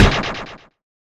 highkicktoe1_hit.wav